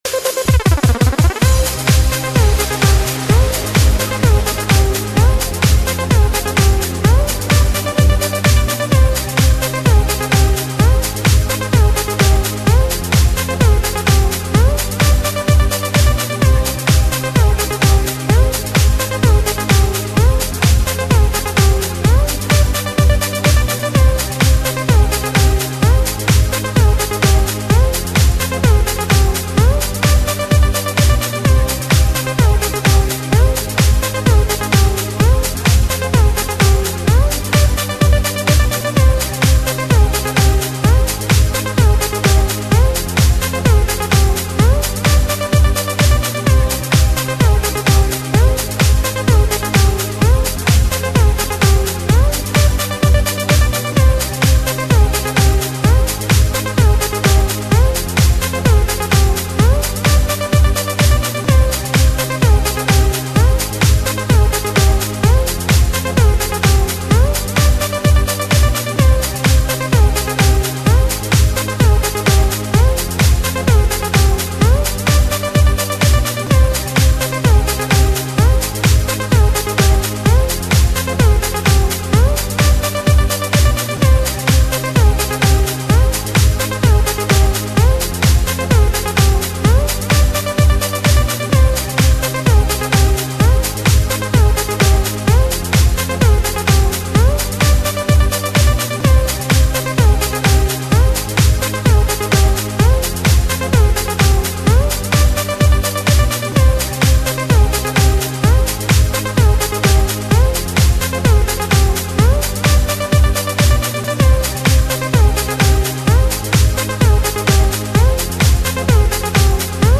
Категория: Клубняк | Добавил: Admin